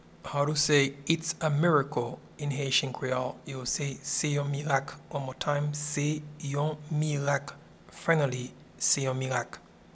Pronunciation and Transcript:
Its-a-miracle-in-Haitian-Creole-Se-yon-mirak.mp3